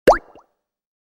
Liquid Pop Bubble Sound Effect
Description: Liquid pop bubble sound effect. This audio delivers a watery drop tone with soft pop and bubbly textures, creating a refreshing, fluid atmosphere.
Liquid-pop-bubble-sound-effect.mp3